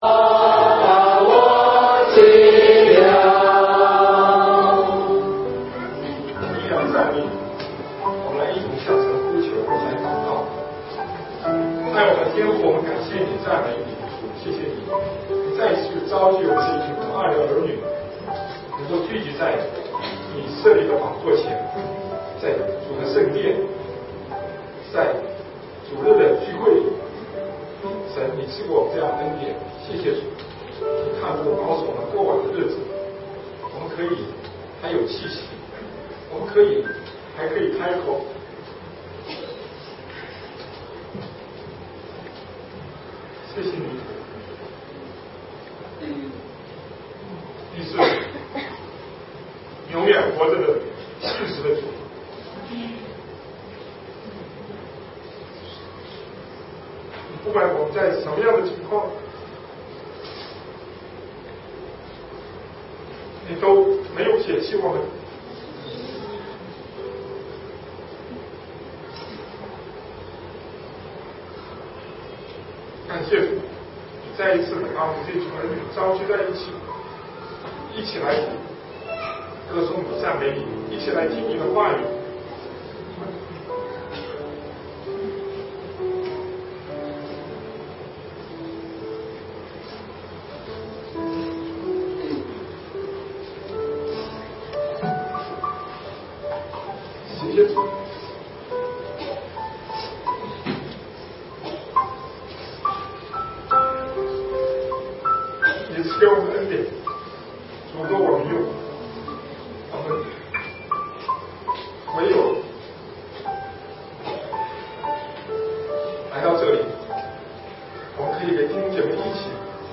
建造教会 第9章 教会的礼仪 2012年11月4日 下午3:51 作者：admin 分类： 建造教会圣经讲道 阅读(4.05K